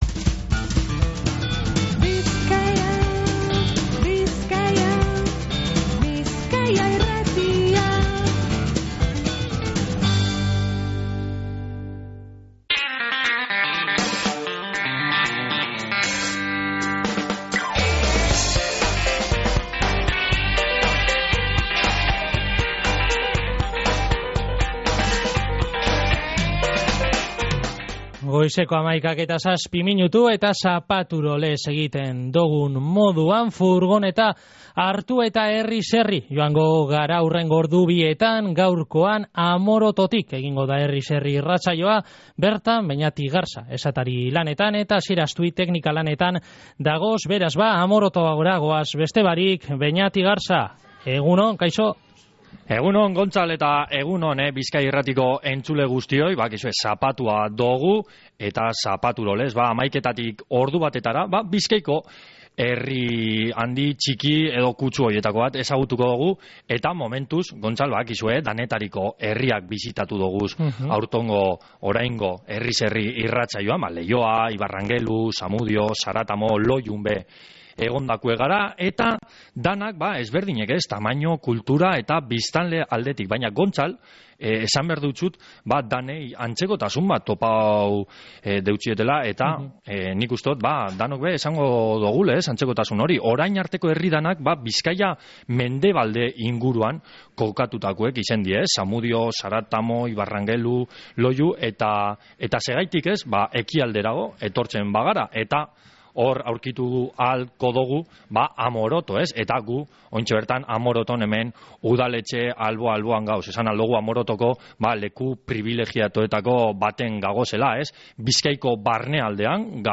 Herriz Herri Amorotoko udaletxetik